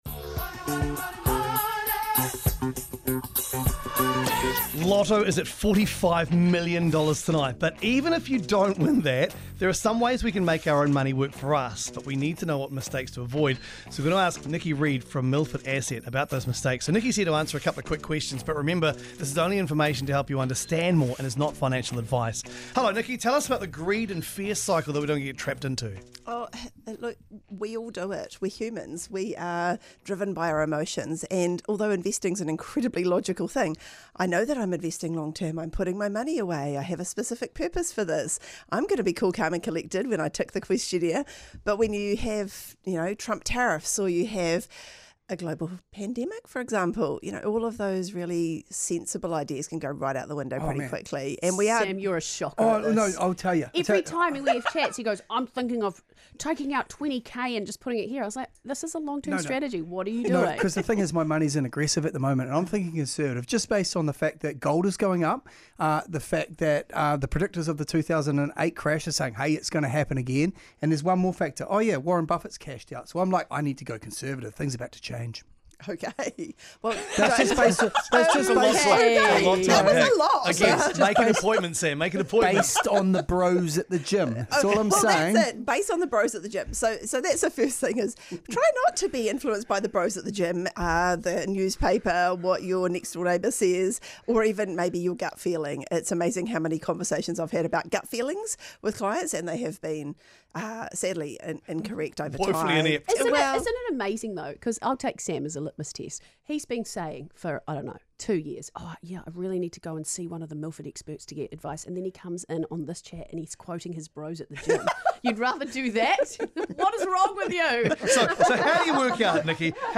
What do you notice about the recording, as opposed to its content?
Coast-Breakfast-x-Milford-Money-Matters-12th-Nov.mp3